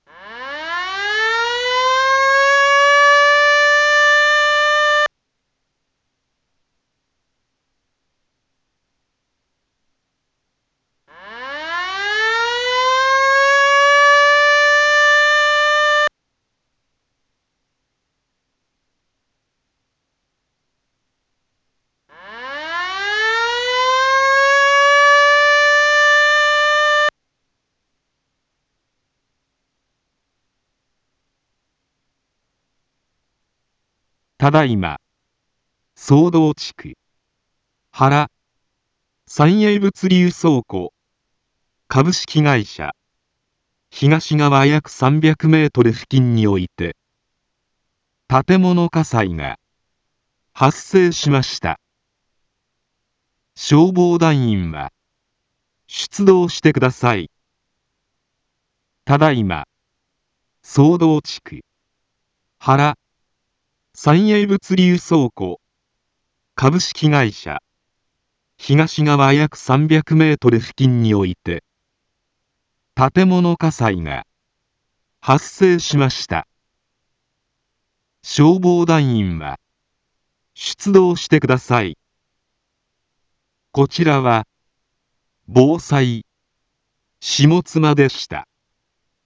一般放送情報
Back Home 一般放送情報 音声放送 再生 一般放送情報 登録日時：2022-10-21 21:05:33 タイトル：火災報 インフォメーション：ただいま、宗道地区、原、サンエイ物流倉庫株式会社東側約300メートル 付近において、 建物火災が、発生しました。